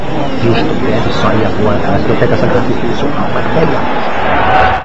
Gravou o trecho e passou ao contrário, da forma como foi gravado originalmente.
Não está muito nítido, mas dá pra se perceber o que o rapaz fala:
O trecho em áudio reverso pode ser tomado como algo semelhante aos famosos 'ovos de páscoa' (os gringos chamam de easter eggs) que os programadores de computador inserem nos programas que eles fazem.